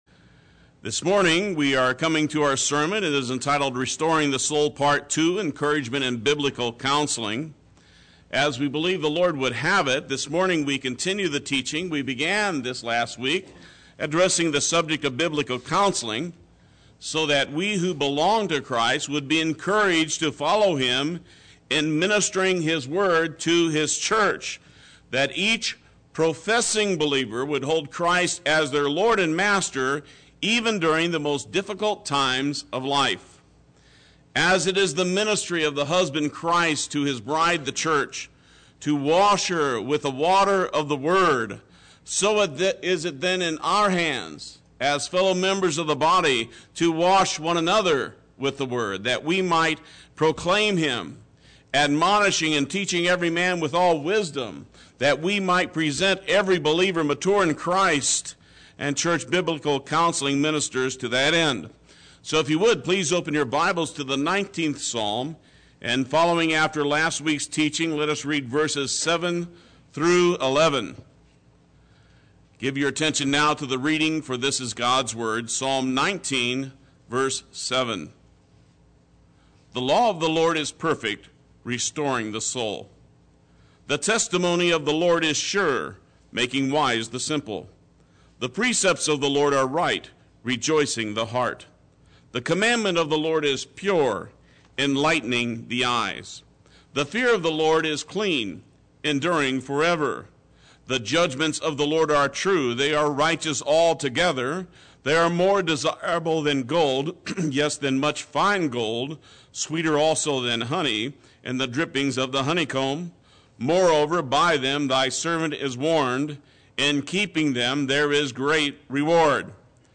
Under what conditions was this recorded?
Part II Sunday Worship